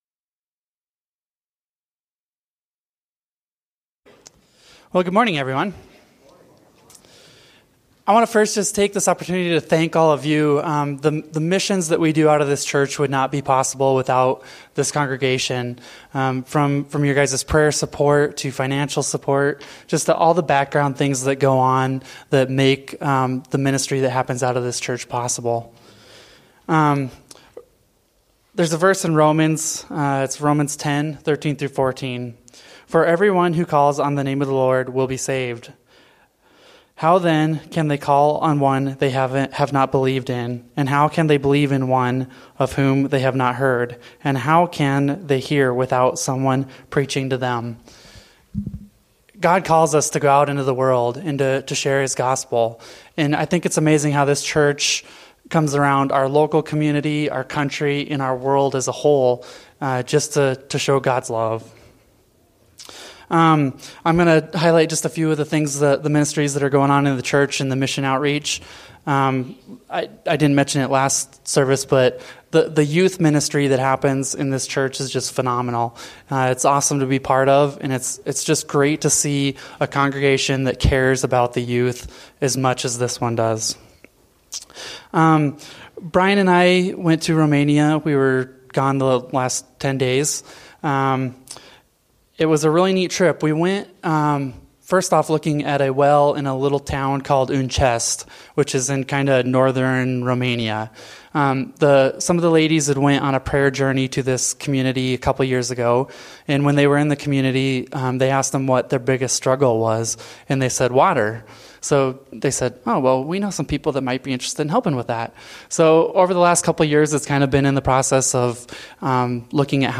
A message from the series "Made for More."